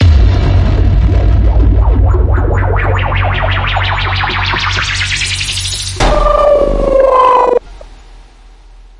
标签： 低保 sounddesign SoundEffect中 sounddesign 未来 延迟 效果 抽象 FX SFX SCI -fi 声音
声道立体声